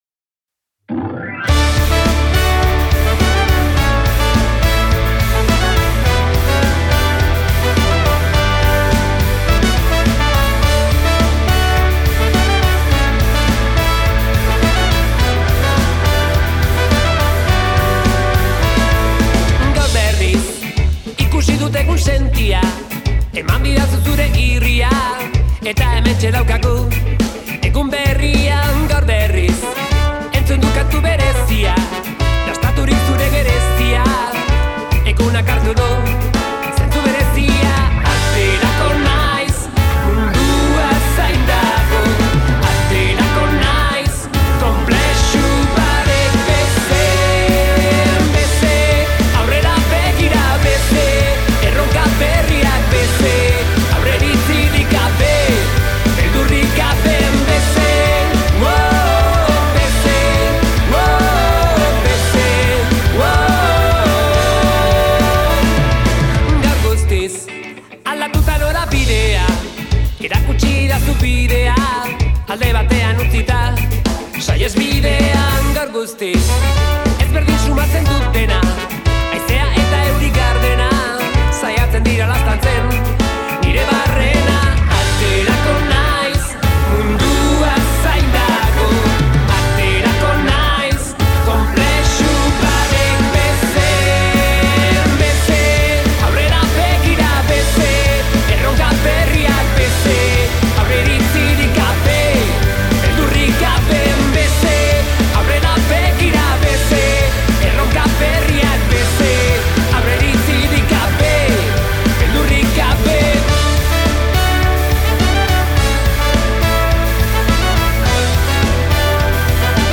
Modus_Operandi_elkarrizketa.mp3